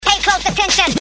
• Samples de  Voz